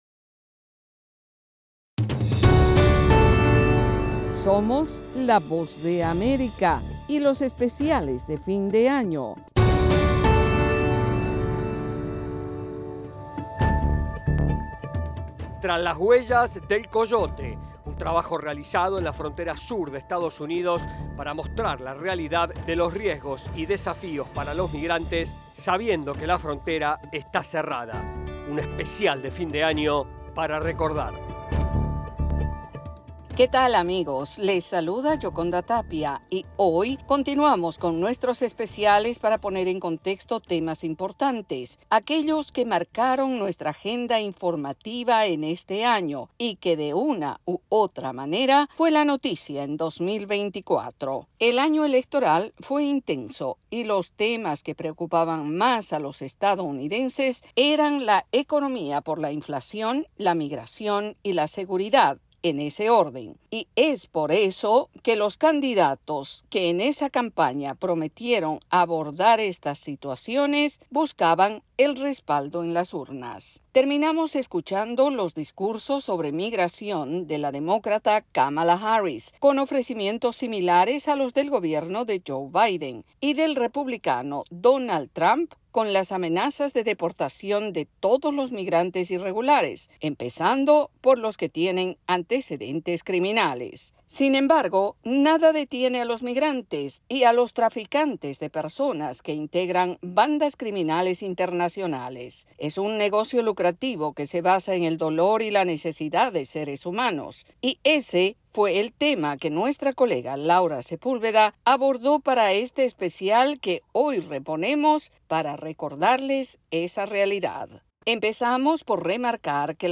ESPECIAL RADIO - TRAS LAS HUELLAS DEL COYOTE.mp3